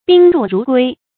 宾入如归 bīn rù rú guī 成语解释 宾客来此如归其家。
ㄅㄧㄣ ㄖㄨˋ ㄖㄨˊ ㄍㄨㄟ